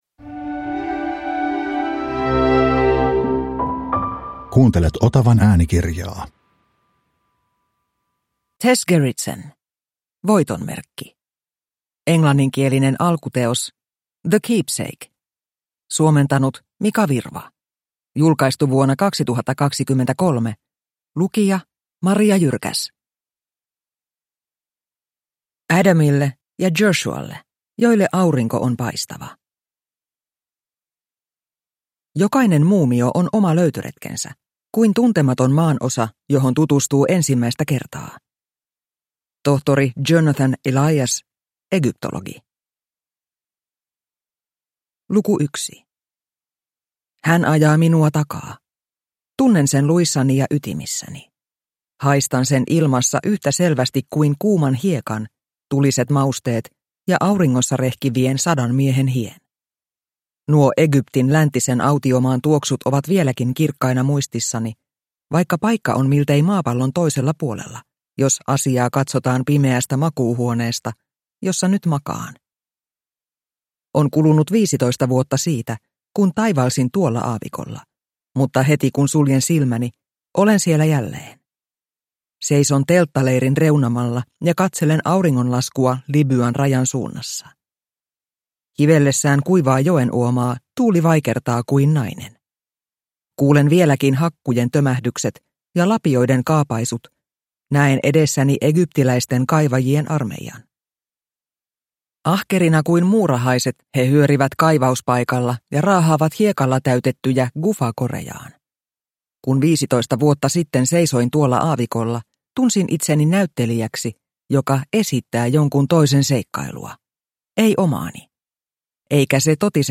Voitonmerkki – Ljudbok